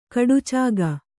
♪ kaḍucāga